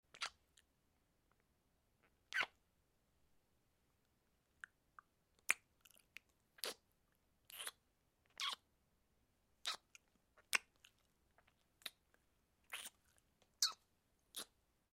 Звуки поцелуев
1. Звуки страстных поцелуев с засосом n2.